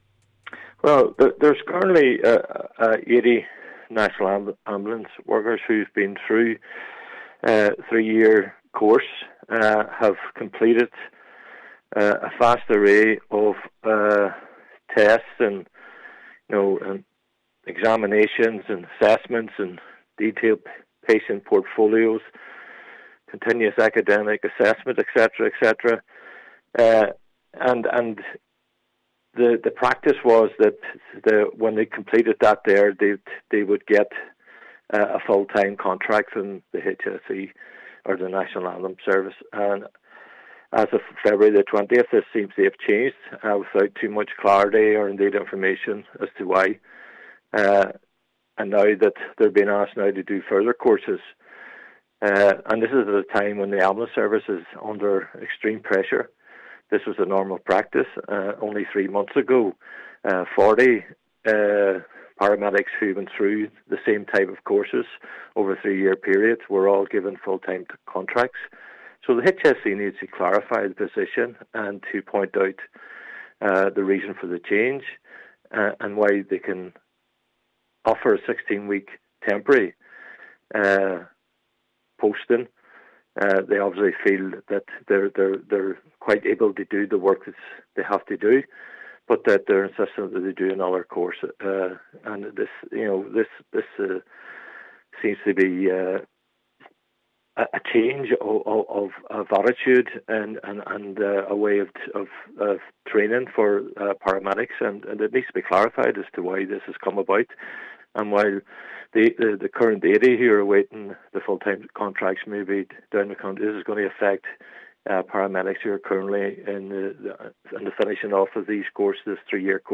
Chairperson of the HSE West/North West Councillor Gerry McMonagle says it is not clear why the system has changed: